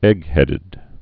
(ĕghĕdĭd)